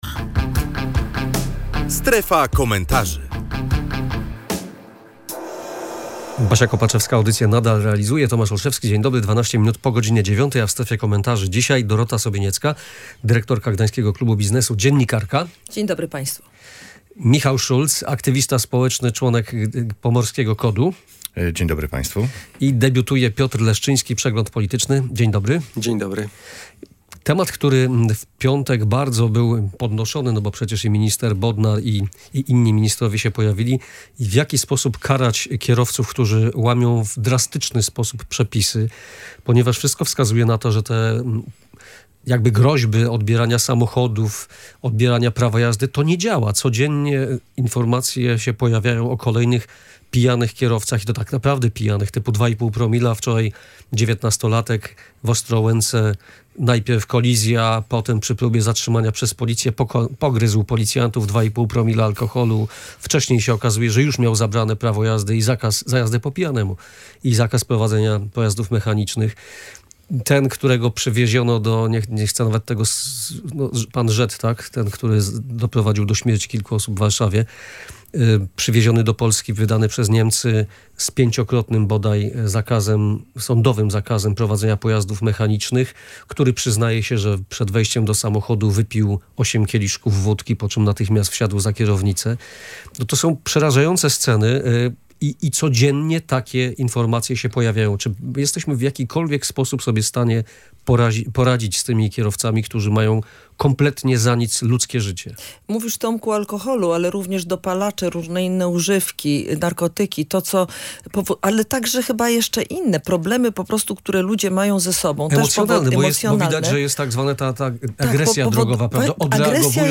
Jak karać kierowców, którzy za nic mają ludzkie życie? Komentatorzy dyskutują o rozwiązaniach